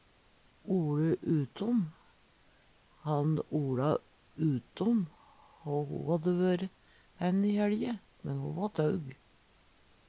oLe utom - Numedalsmål (en-US)
Tilleggsopplysningar kan og si "oLe frampå" See also taug (Veggli) Hør på dette ordet